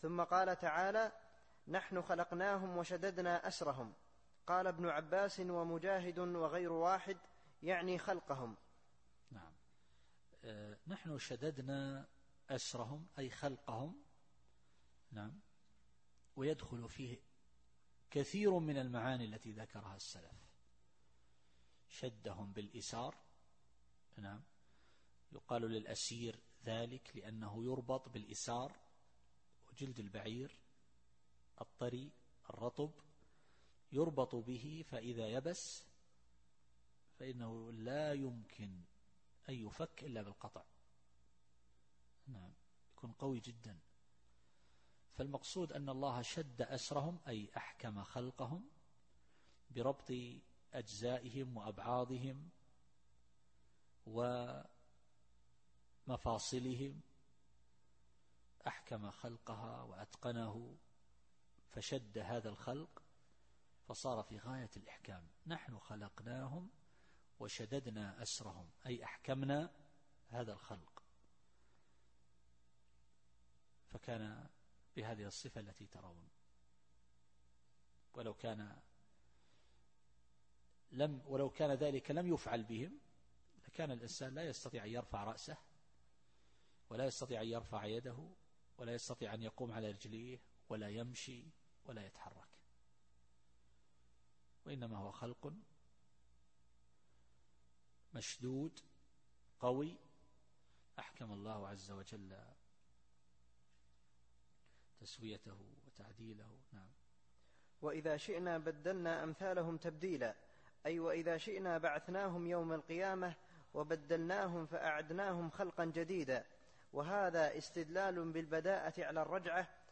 التفسير الصوتي [الإنسان / 28]